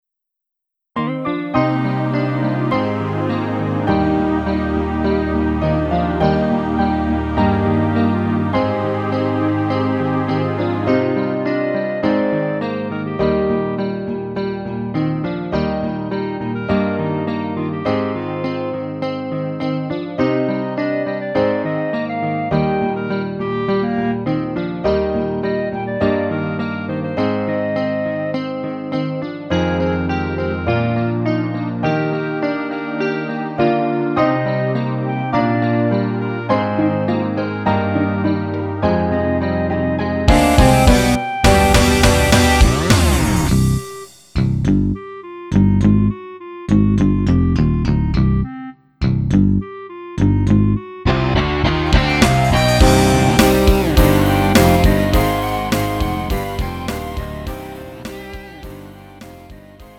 음정 -1키
장르 가요 구분 Lite MR